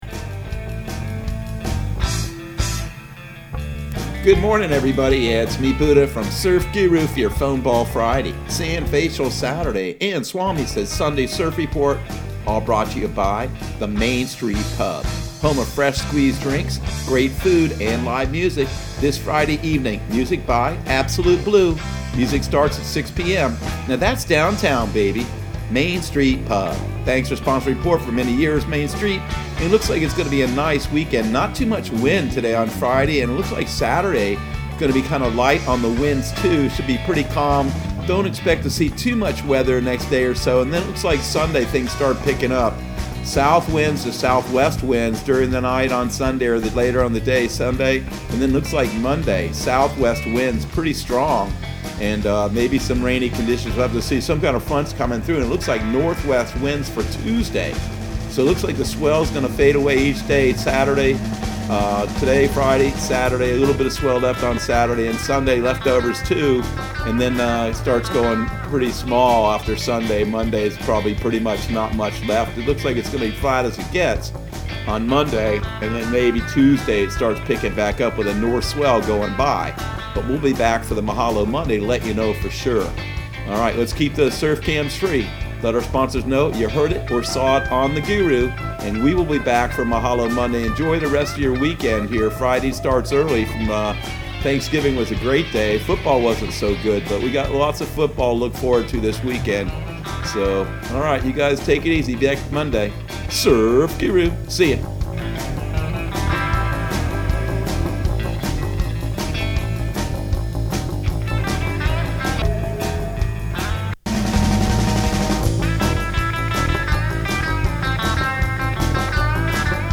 Surf Guru Surf Report and Forecast 11/27/2020 Audio surf report and surf forecast on November 27 for Central Florida and the Southeast.